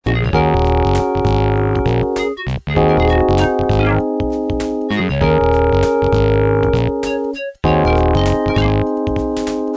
General MIDI test       [Sun/Next]